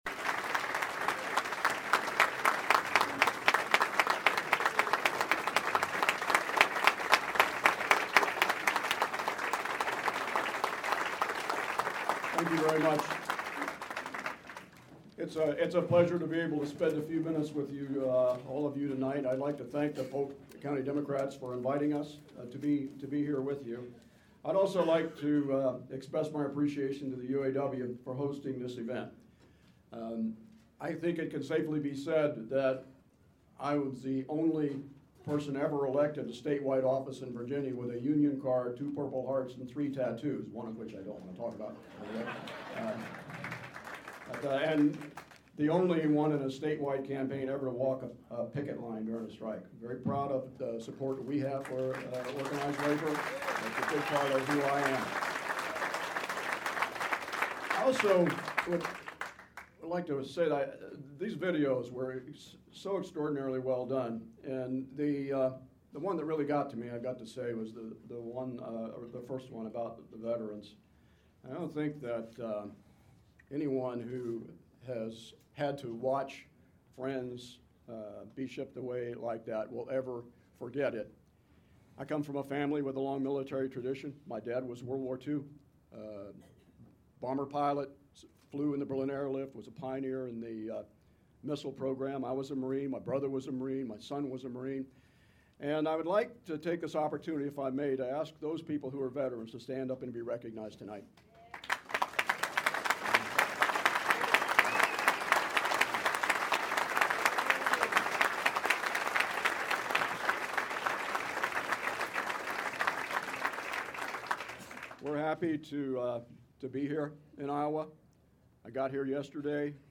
Webb spoke to over 250 people gathered in a union hall for a Polk County Democrats’ fundraiser.